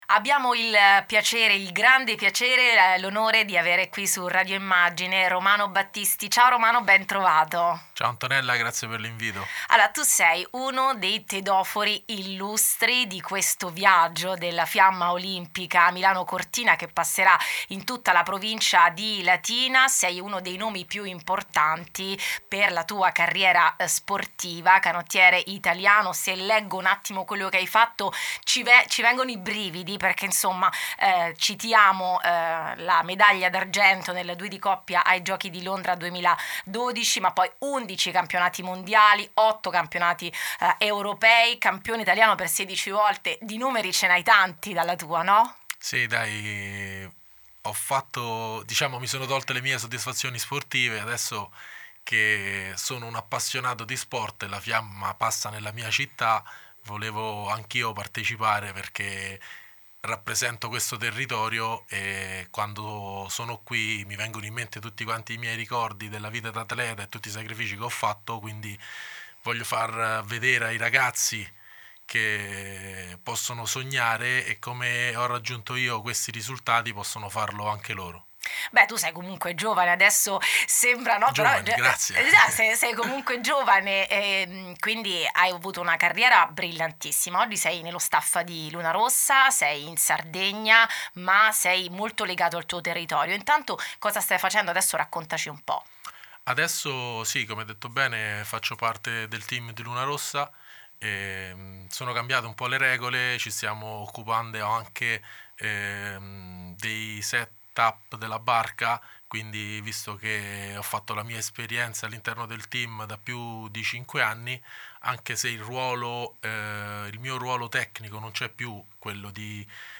Il Campione Olimpico Romano Battisti, ospite di Radio Immagine